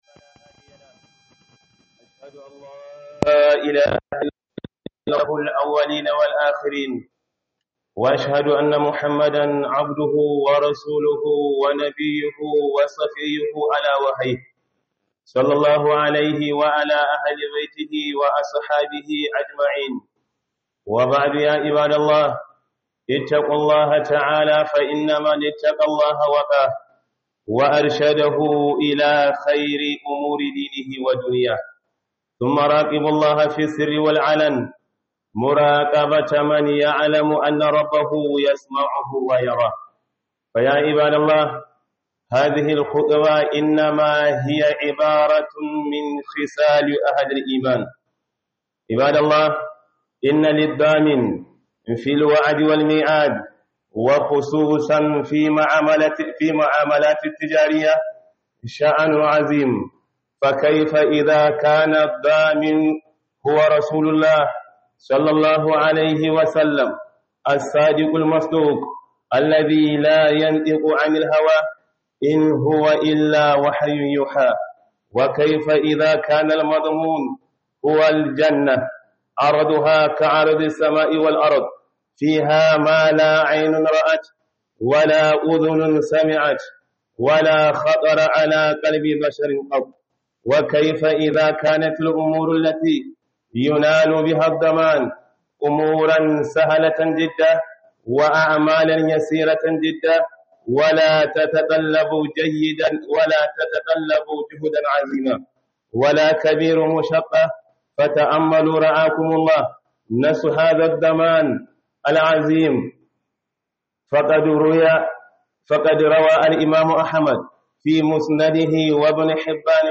Khudubar Low-cost Ningi - Rikon Amana